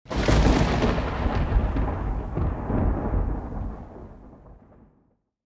Download Thunderclap sound effect for free.
Thunderclap